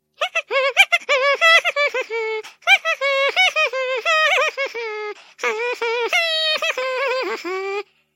Звуки пения людей
Мультяшный герой тихонько напевает песенку